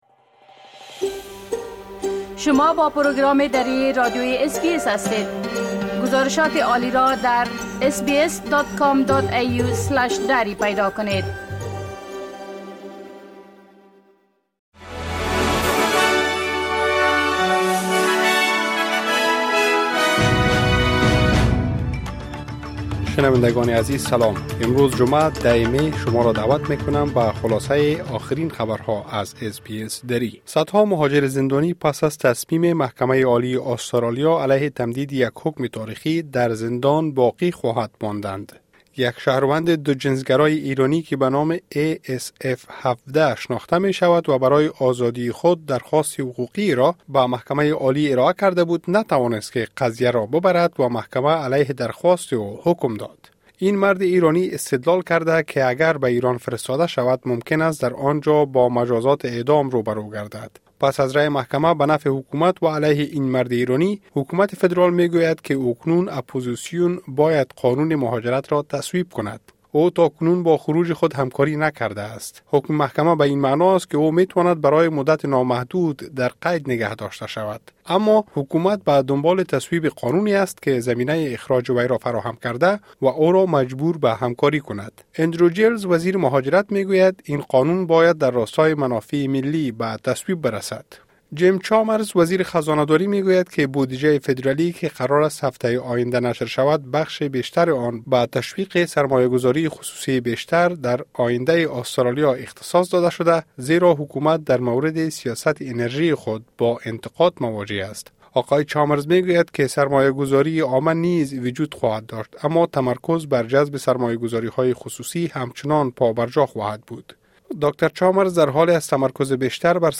خلاصۀ مهمترين اخبار روز از بخش درى راديوى اس بى اس|۱۰ می ۲۰۲۴